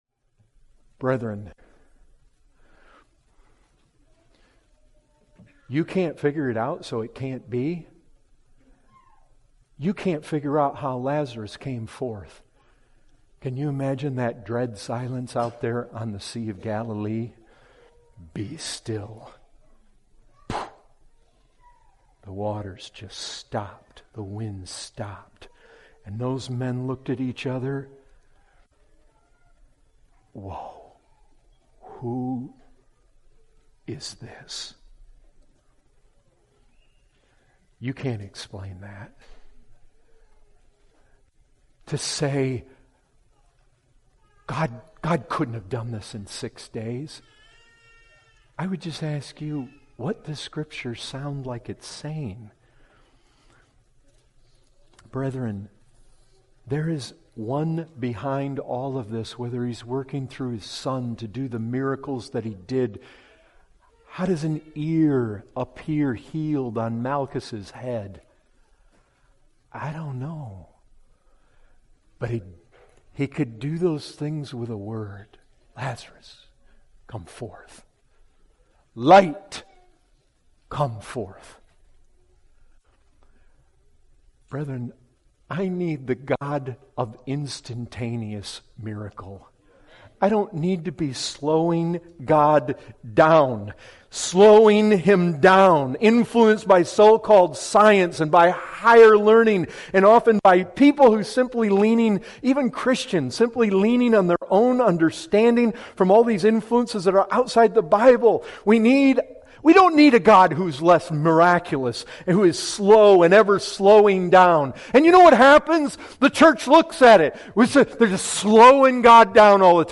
This excerpt was taken from the full sermon, “ How Should We Interpret the Creation Account? “.